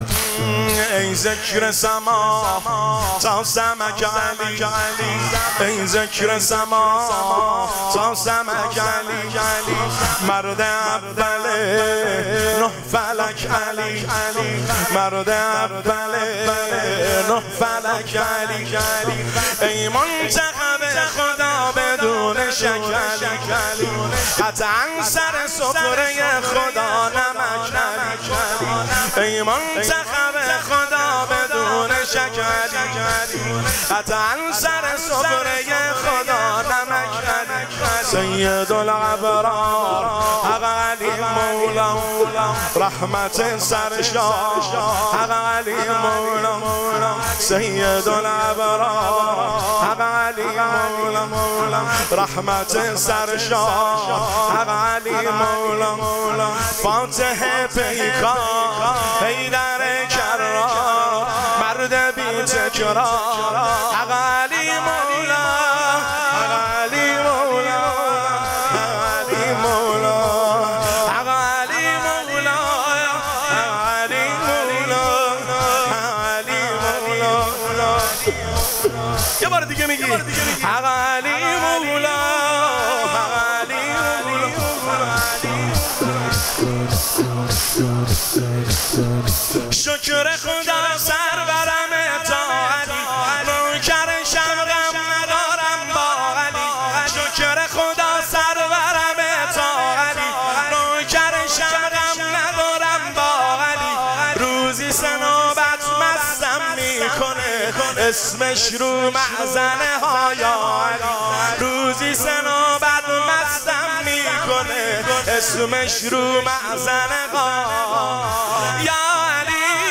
تک  شب دوم فاطمیه دوم 1404
هیئت بین الحرمین طهران
دانلود با کیفیت LIVE